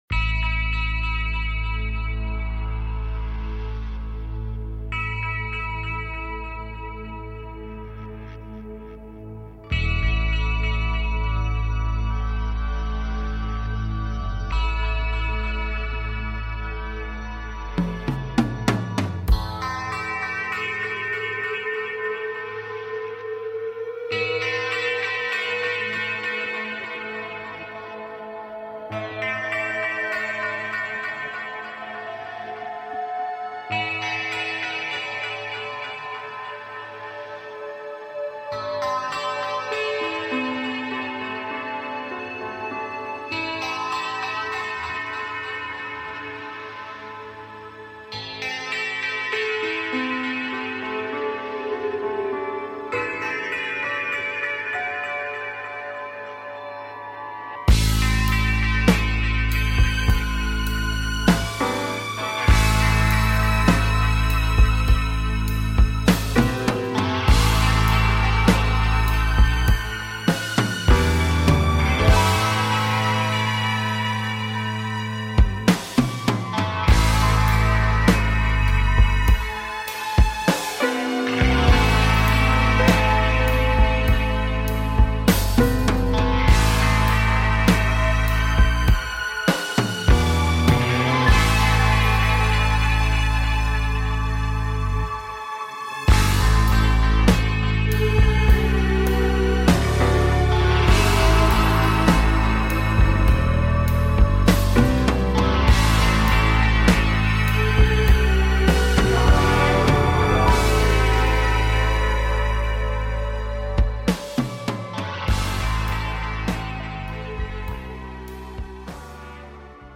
Show on Homesteading and taking caller questions